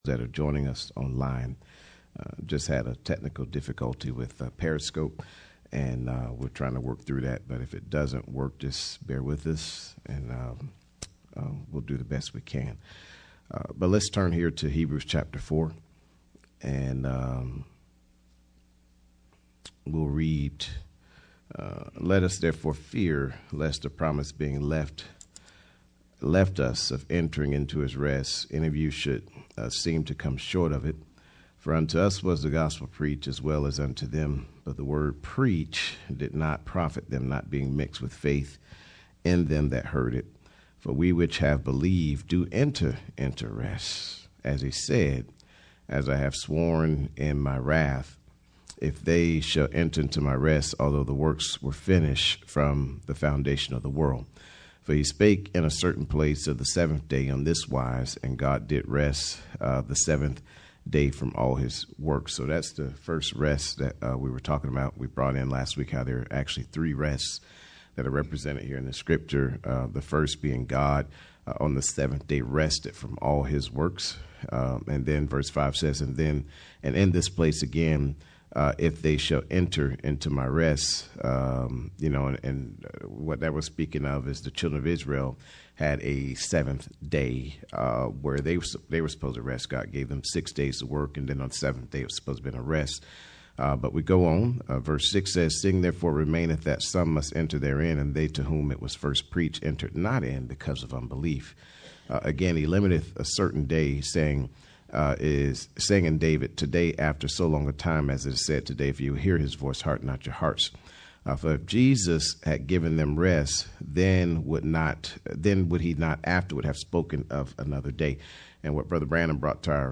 Series: Bible Study